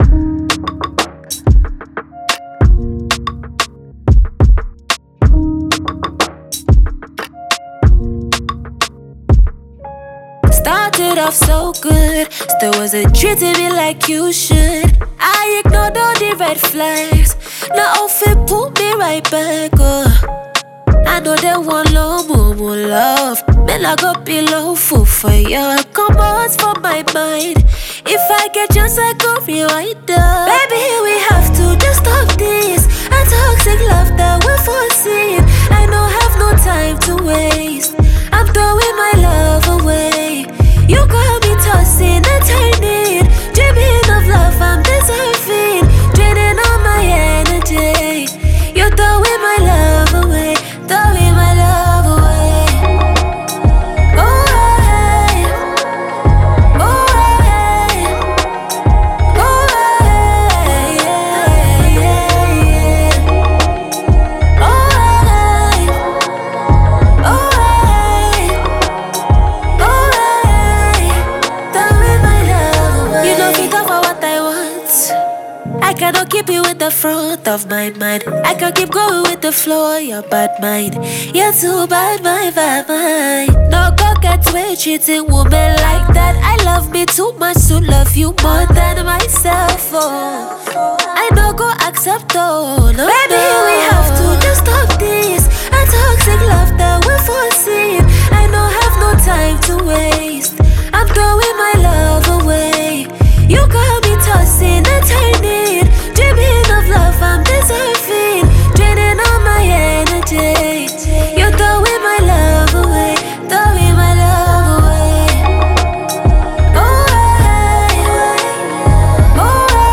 Afro-fusion